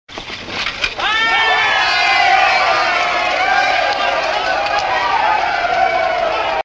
PEOPLE CHEER IN PUBLIC SQUARE.mp3
Original creative-commons licensed sounds for DJ's and music producers, recorded with high quality studio microphones.
people_cheer_in_public_square_bpl.ogg